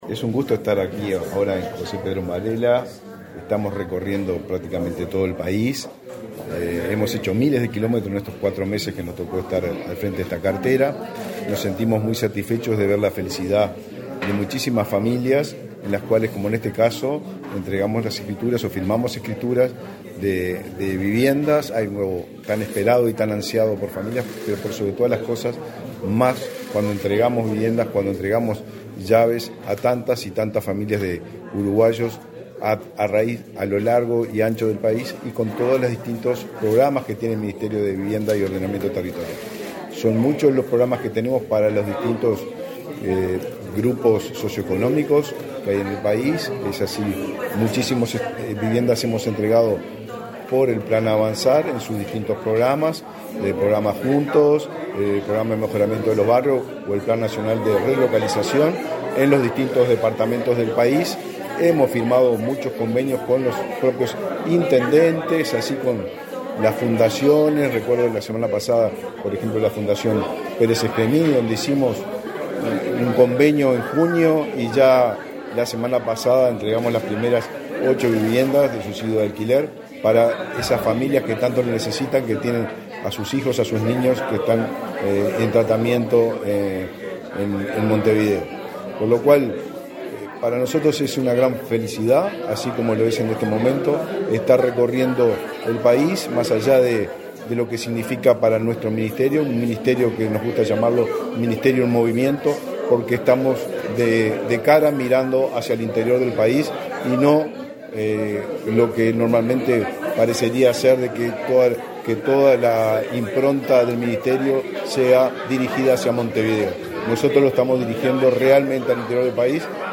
Declaraciones a la prensa del ministro de Vivienda, Raúl Lozano
Declaraciones a la prensa del ministro de Vivienda, Raúl Lozano 19/09/2023 Compartir Facebook X Copiar enlace WhatsApp LinkedIn Tras participar en la entrega de títulos de propiedad a 16 familias de José Pedro Varela, en el departamento de Lavalleja, este 19 de setiembre, el ministro Raúl Lozano, realizó declaraciones a la prensa.